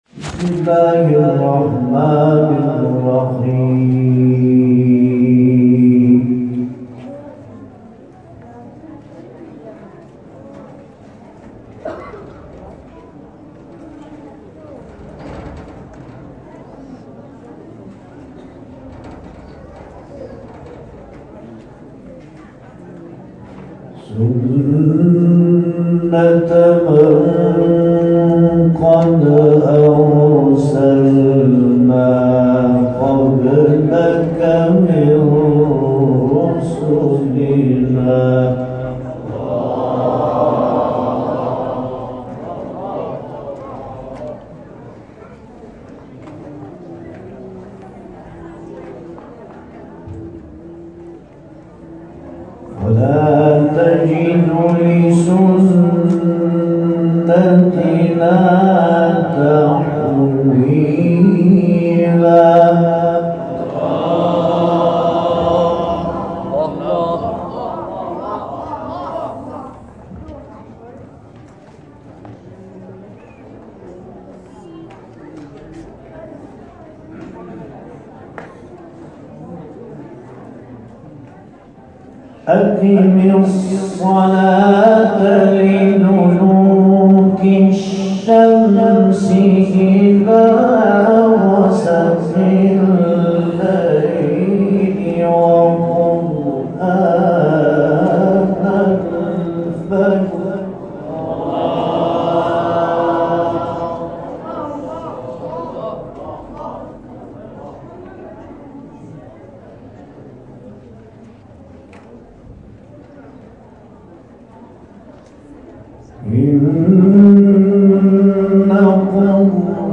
محفل قرآنی همراه با اختتامیه طرح فراز آسمانی
در ادامه تلاوت